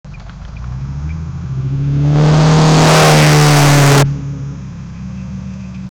a car with a rough engine going fast and the exhaust popping from time to time
a-car-with-a-rough-j37vzaus.wav